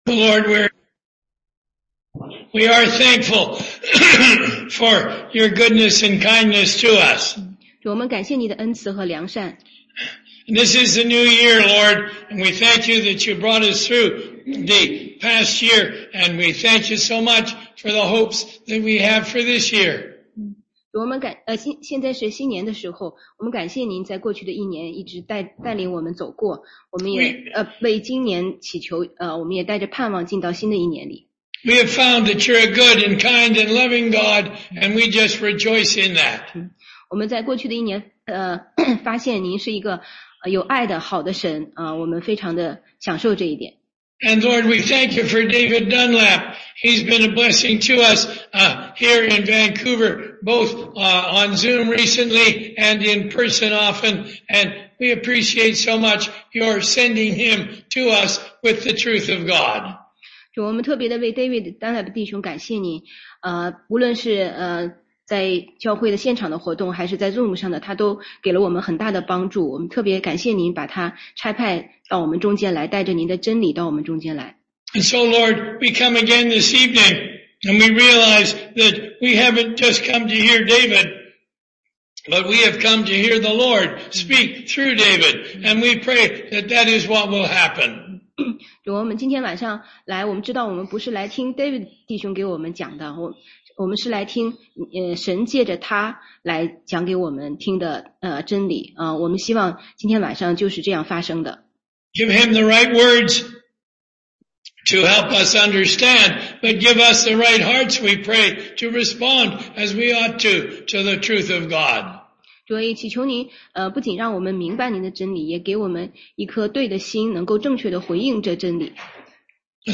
16街讲道录音
中英文查经